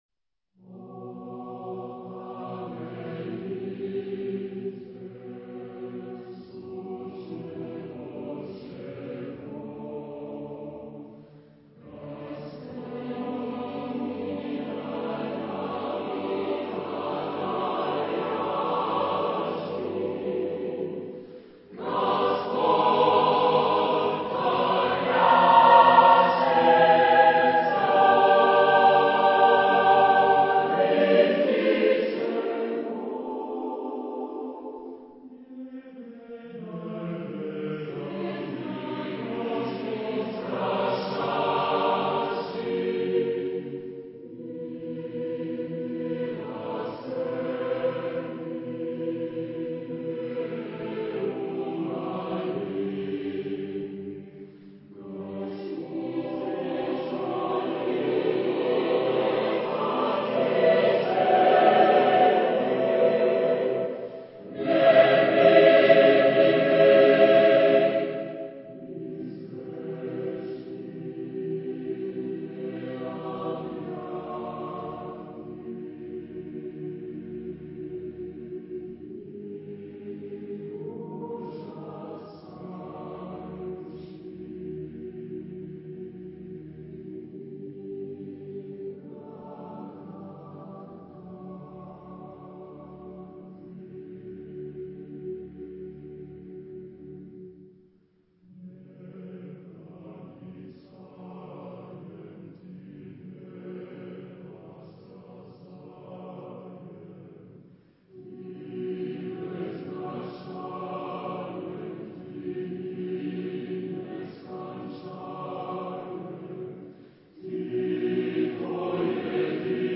Type de choeur : 4S-4A-4T-4B  (16 voix mixtes )